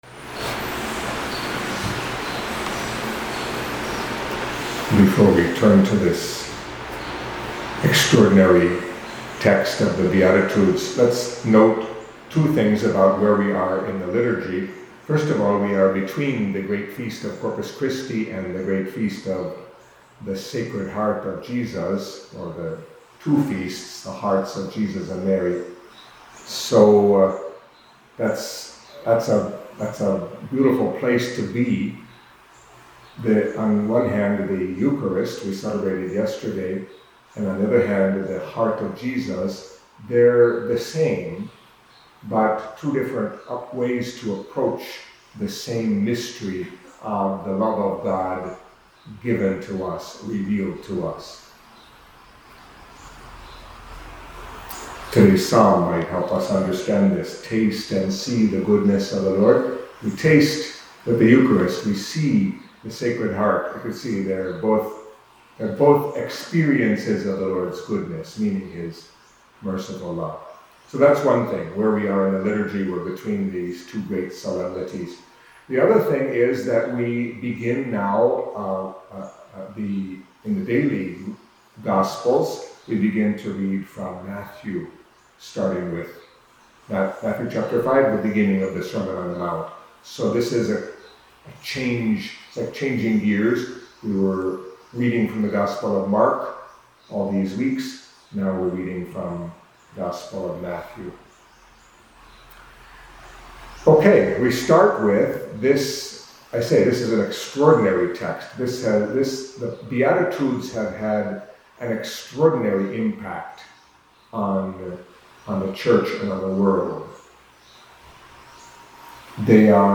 Catholic Mass homily for Monday of the 10th Week in Ordinary Time